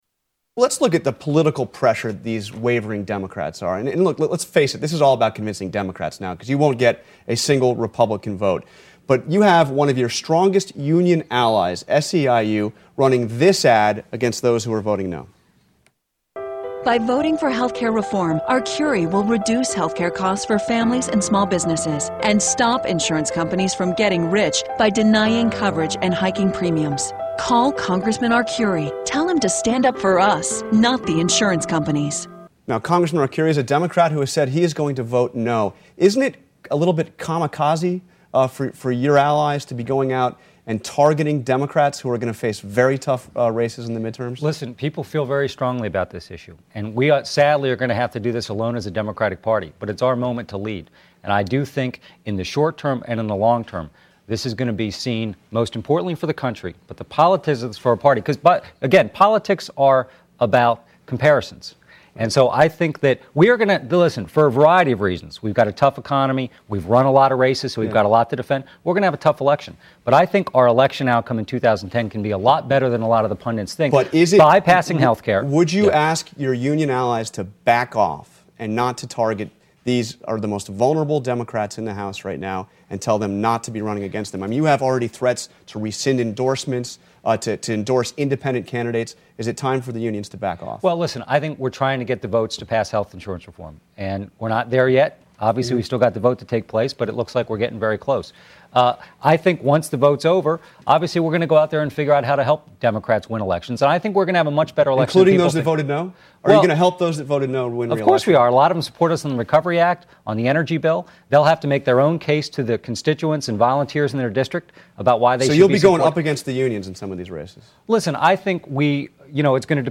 Karl Rove and David Plouffe Debate prt 3